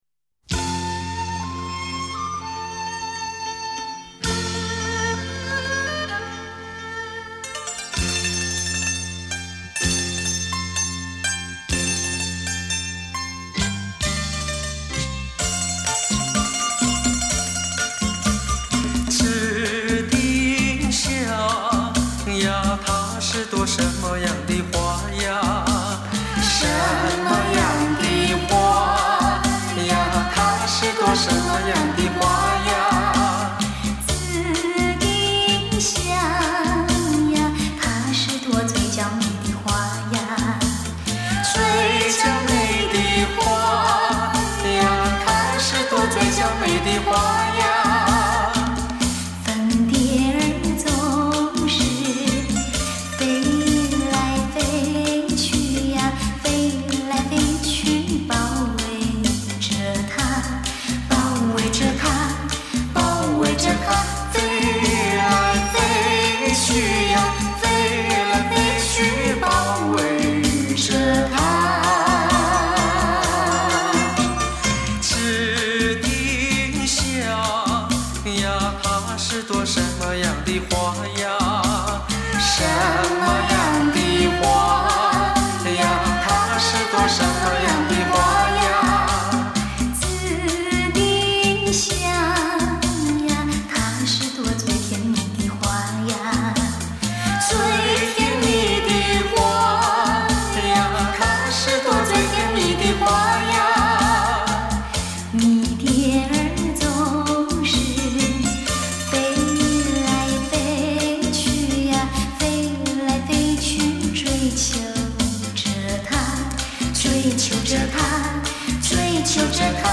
这个声音，曾经风靡大陆，畅销六百万张。